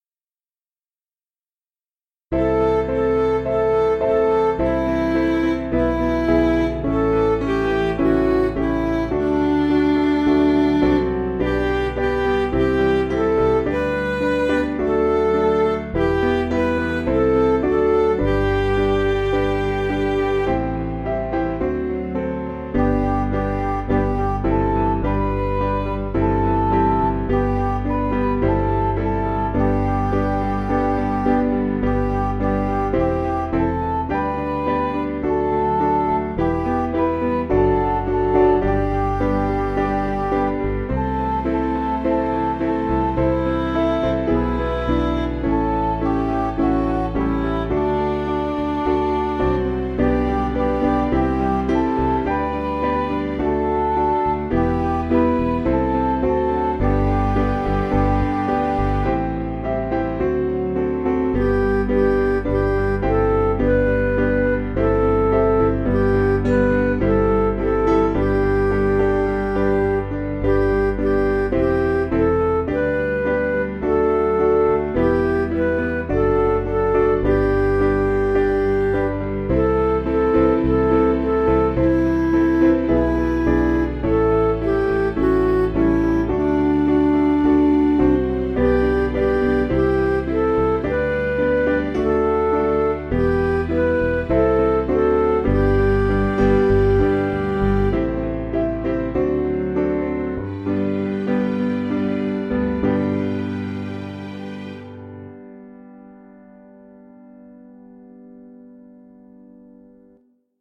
Piano & Instrumental
(CM)   2/G